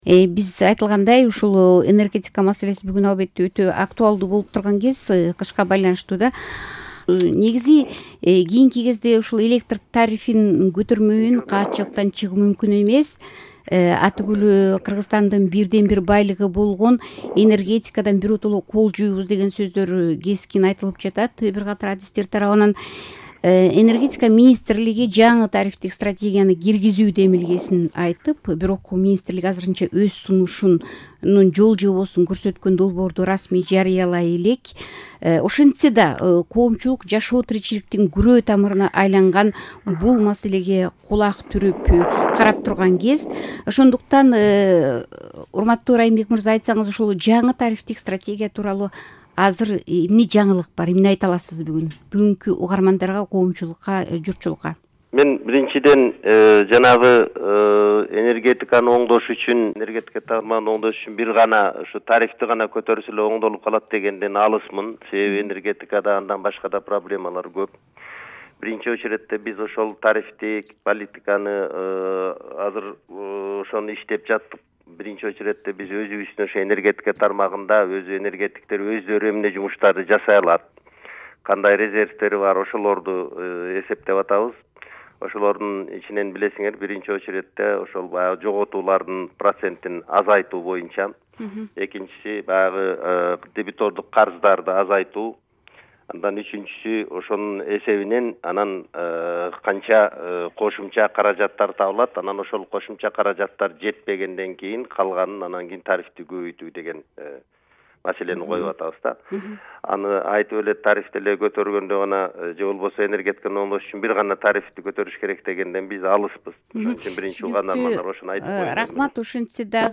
Энергетика тууралуу талкуу